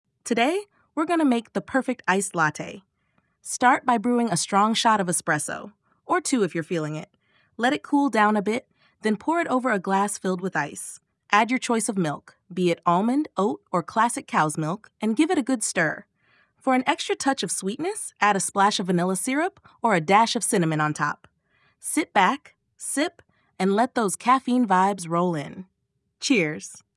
新语音功能由新的文本转语音模型提供支持。该模型能仅从文本和几秒钟的样本语音中生成类似人类的音频。
OpenAI展示了一些语音样本，比如讲制作冰拿铁的食谱，五种不同的声音效果如下：